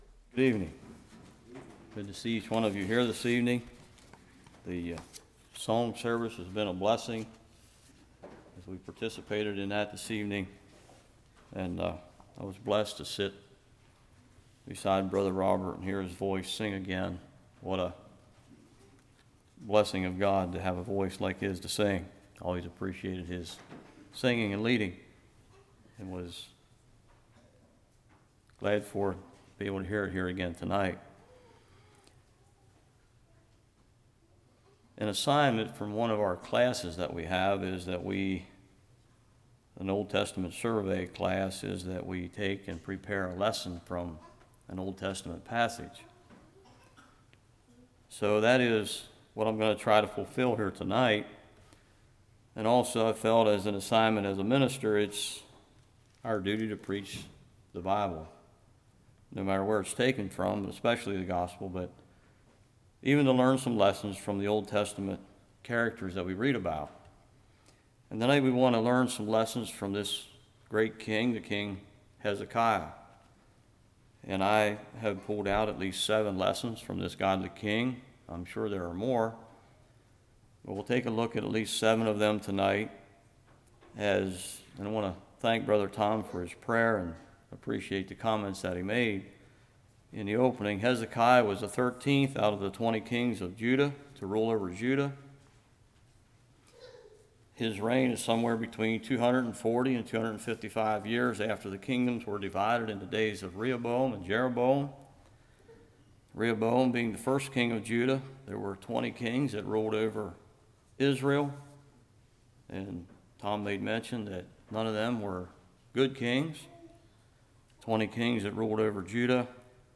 2 Kings 18:1-8 Service Type: Evening Leadership Faithful Garments white « What Happened to the Brethren Elder?